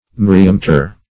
Myriameter \Myr"i*a*me`ter\, Myriametre \Myr"i*a*me`tre\, n. [F.